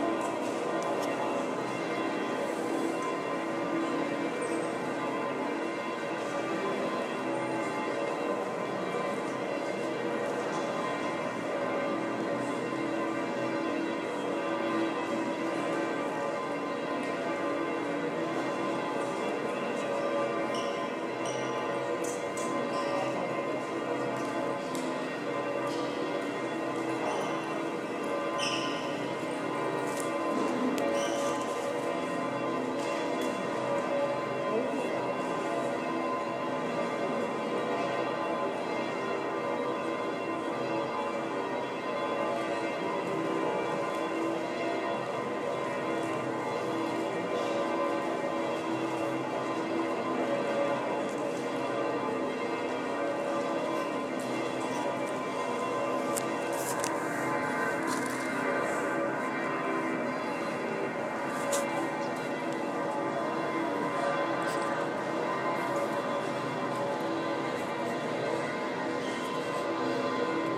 Bells of St. Peter's Basilica
Beginning at 4:30 p.m. on Monday, December 22, 2014, the bells of St. Peter's Basilica in Vatican City rang for10 minutes. This is a short excerpt.